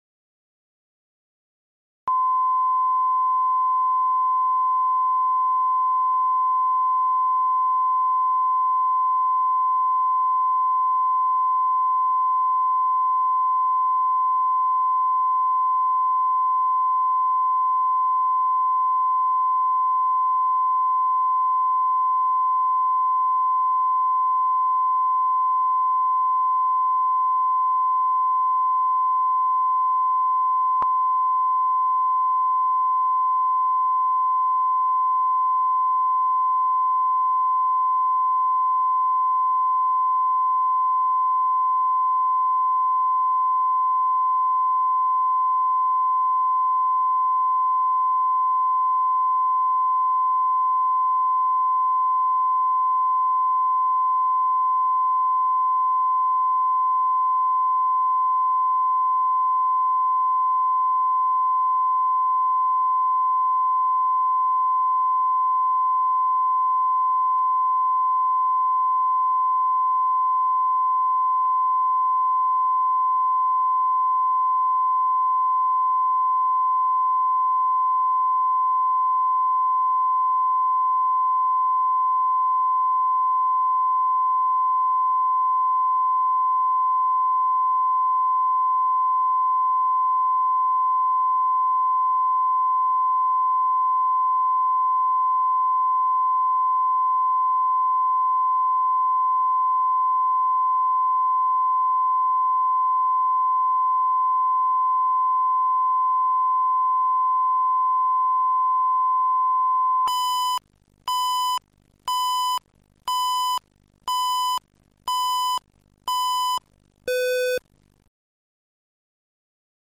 Аудиокнига Мой папусюлечка | Библиотека аудиокниг